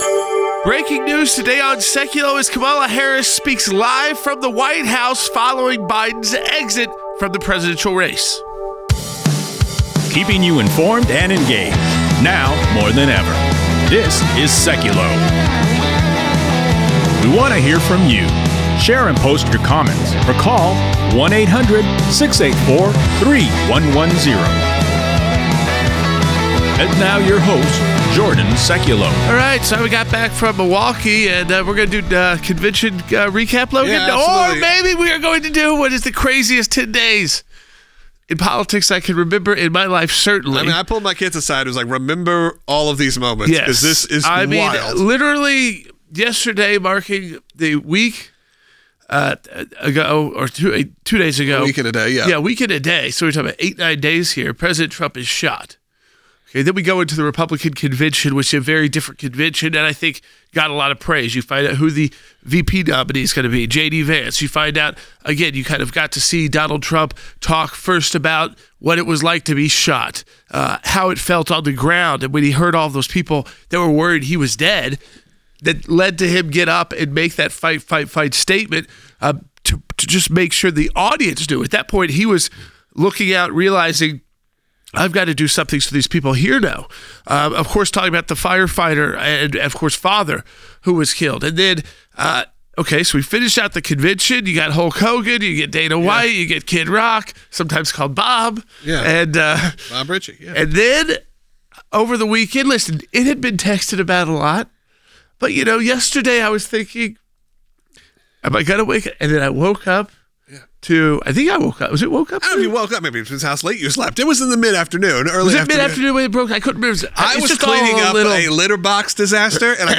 BREAKING: Kamala Harris Speaks LIVE From White House Following Biden Exit Podcast with Jay Sekulow & Jordan Sekulow
She spoke live at the White House today to honor the NCAA tournament champions and is now the presumptive Democratic nominee for the 2024 presidential election.